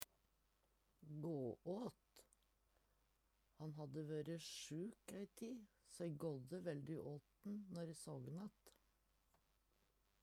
gå åt - Numedalsmål (en-US)